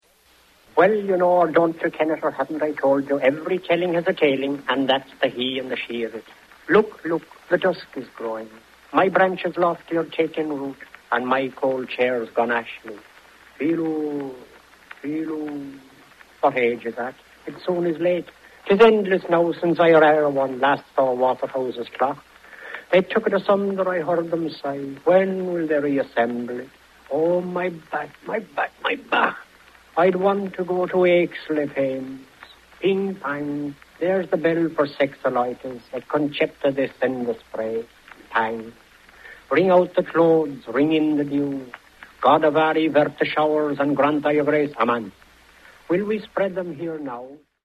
Here’s a snippet of Joyce reading from Finnegan’s Wake.